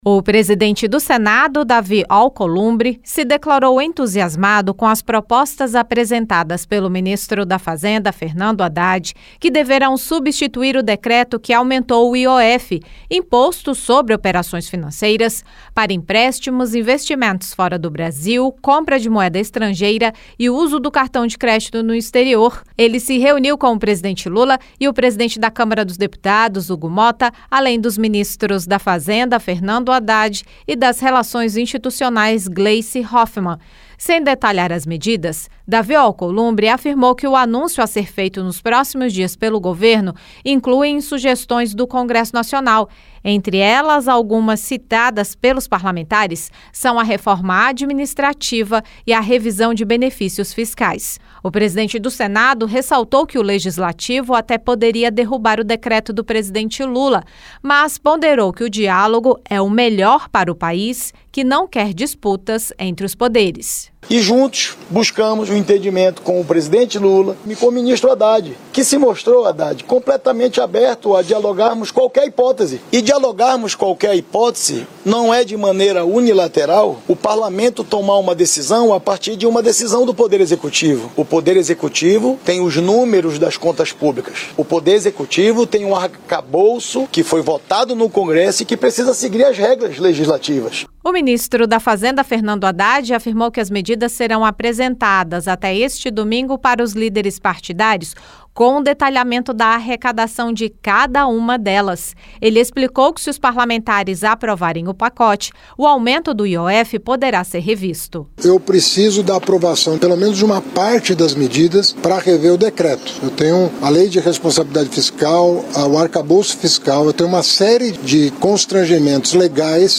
Reprodução TV Senado